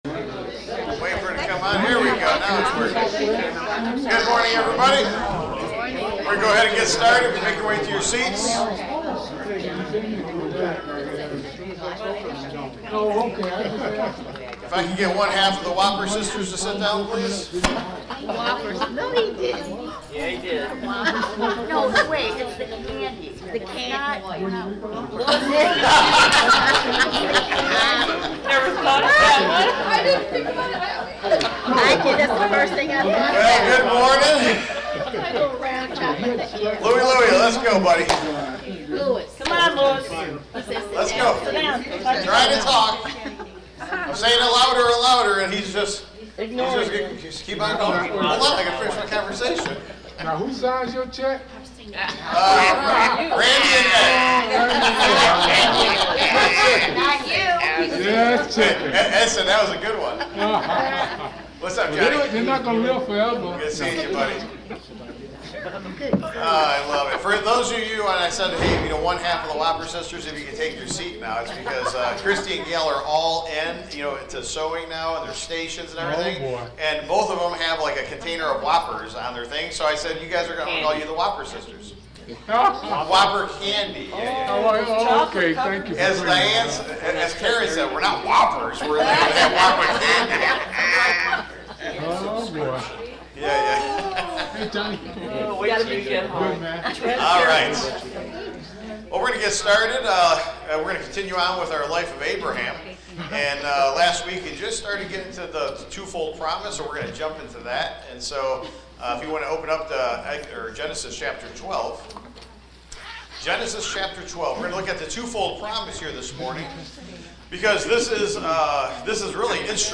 Taught live Sunday, May 25, 2025